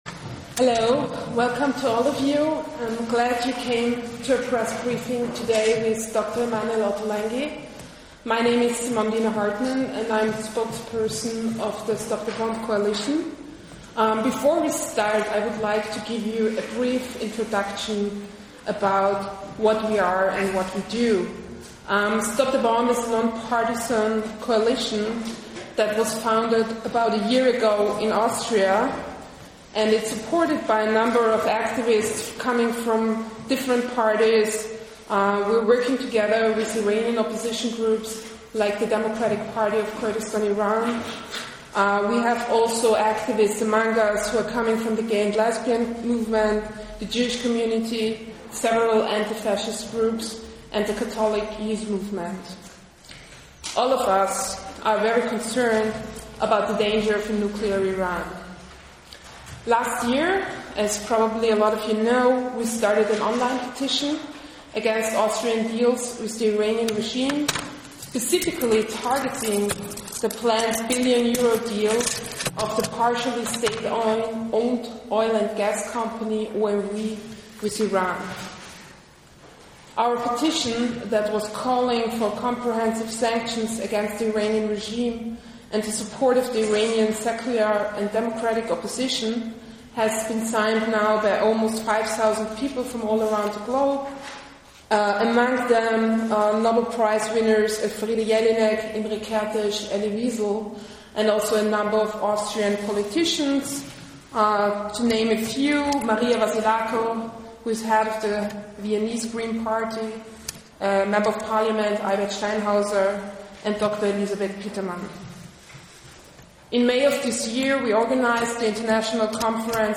Mittwoch, 10. Dezember 2008, Wien